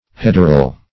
Hederal \Hed"er*al\, a. Of or pertaining to ivy.